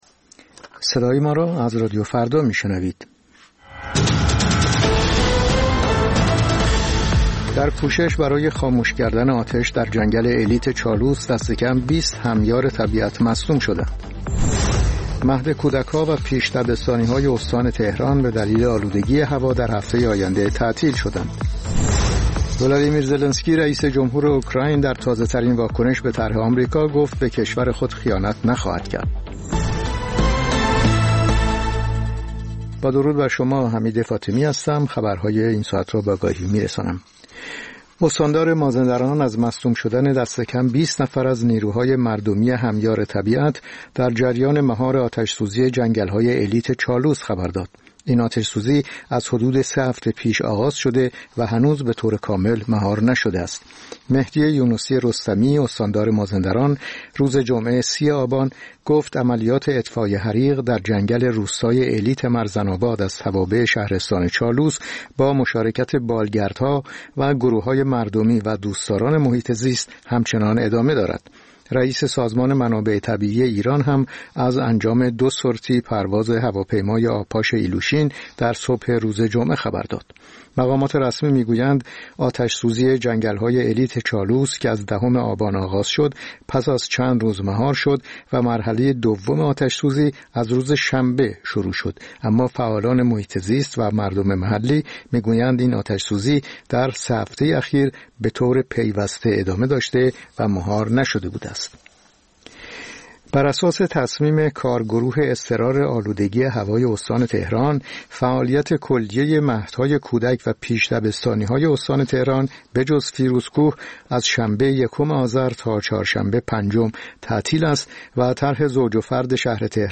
سرخط خبرها ۸:۰۰